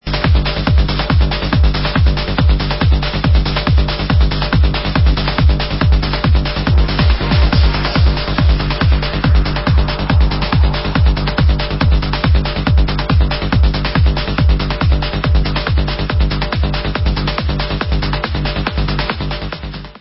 sledovat novinky v oddělení Dance/Trance